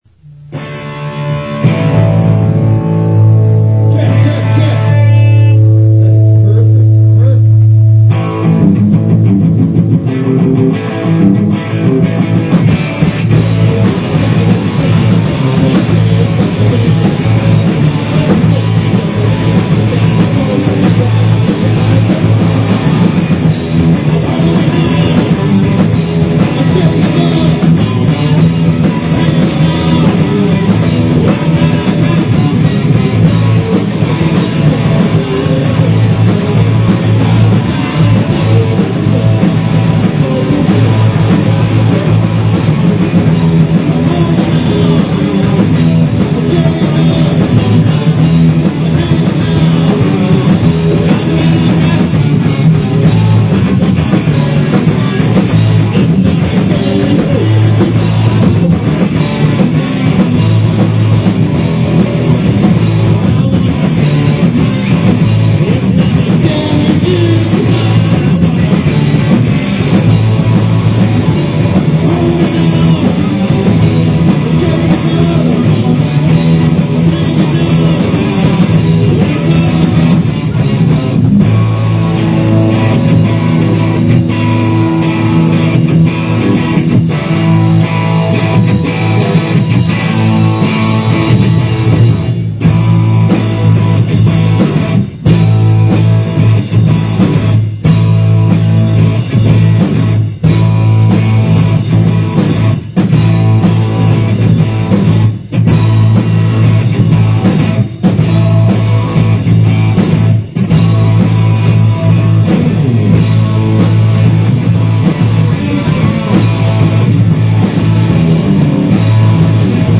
Live at Duggan's Pub